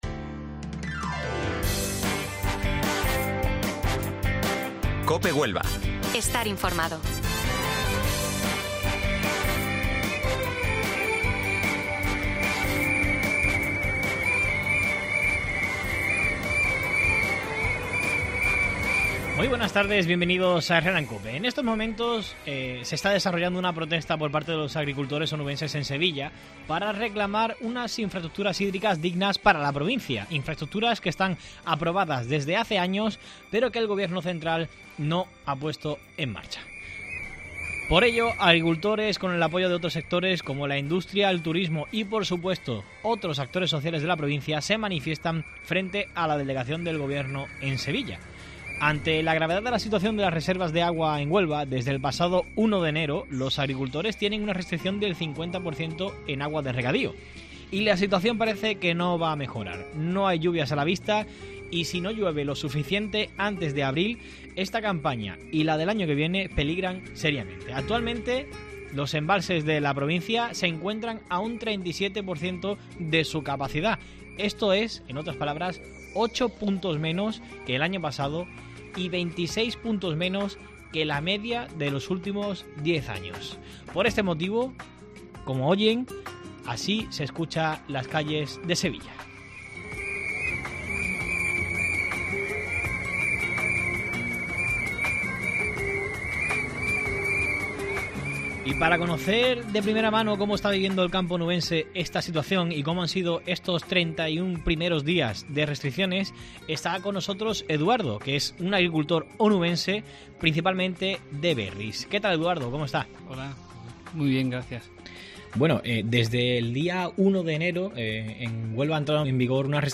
Hoy en Herrera en COPE hablamos con un agricultor onubense que nos cuenta cómo está viviendo el campo el primer mes de restricciones de agua de regadío al 50%.